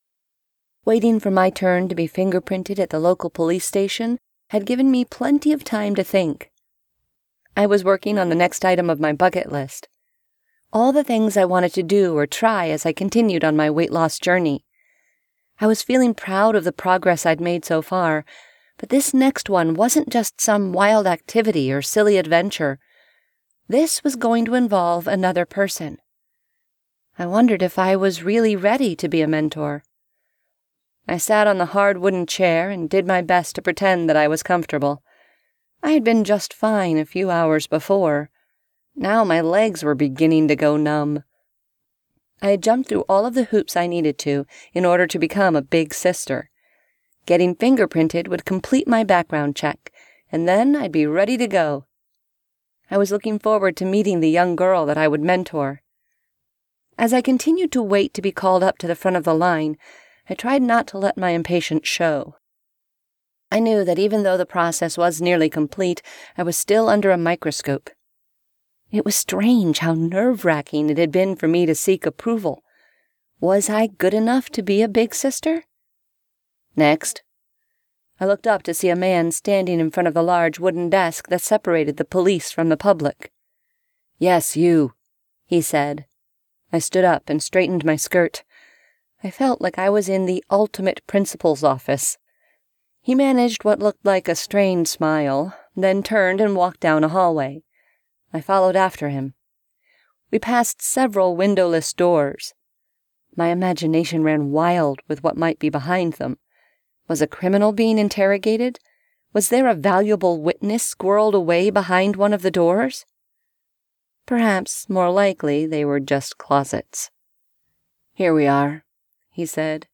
( Audible , Amazon and iTunes ) Click here to find out how to get this audiobook for FREE!